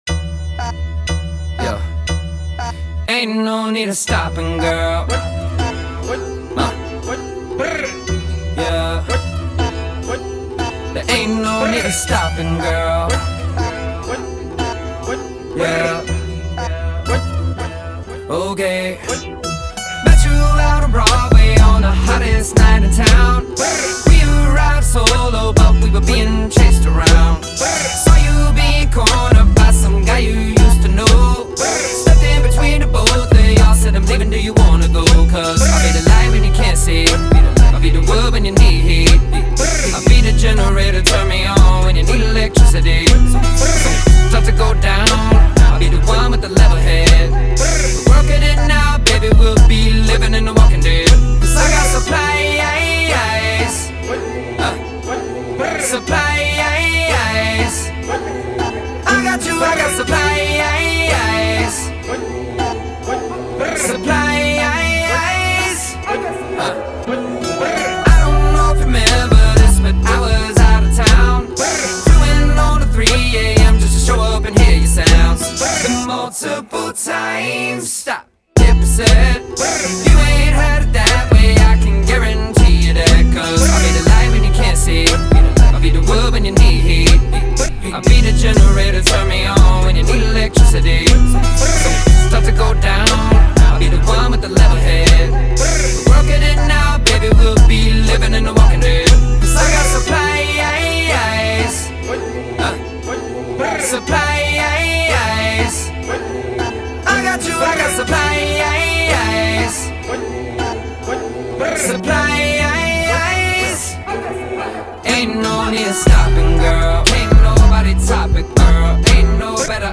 a ‘trap-pop’ track entitled